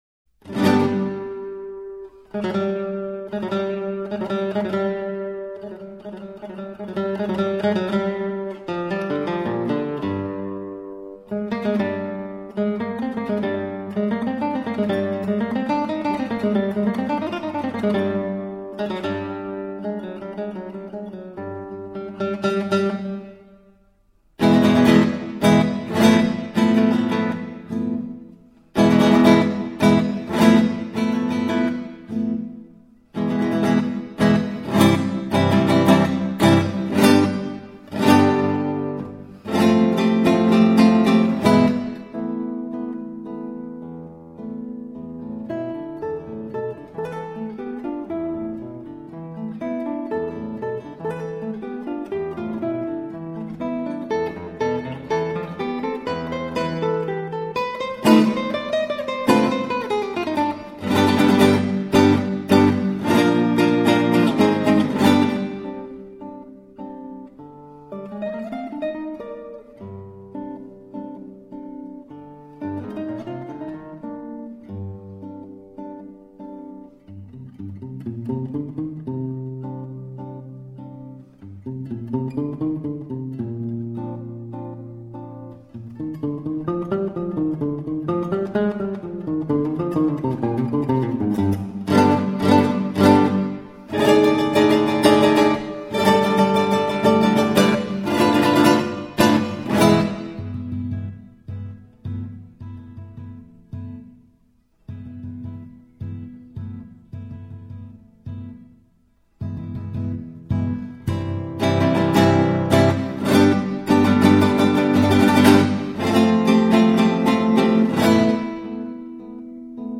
0211-吉他名曲米勒之舞.mp3